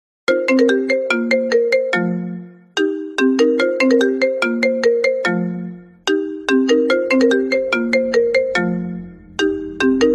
Sound Effects
Phone_ring